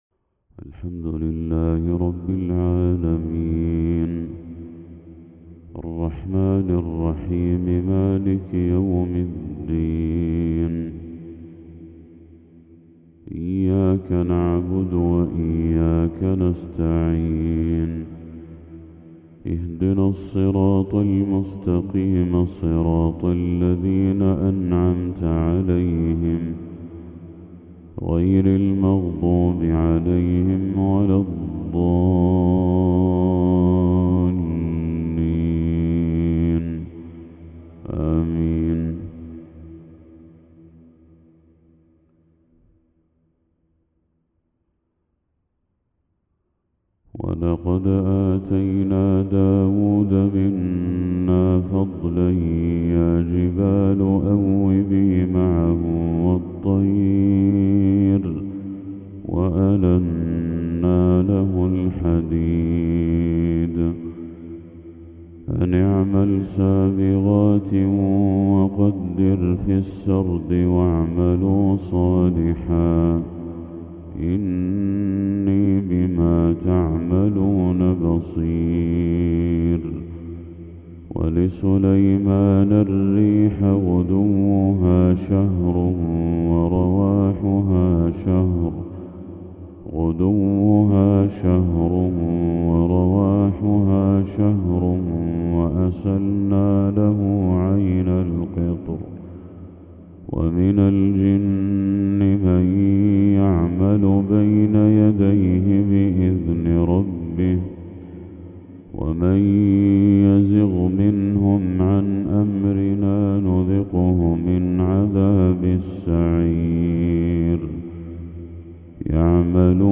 تلاوة هادئة بأداء آسر من سورة سبأ للشيخ بدر التركي | فجر 2 ذو الحجة 1445هـ > 1445هـ > تلاوات الشيخ بدر التركي > المزيد - تلاوات الحرمين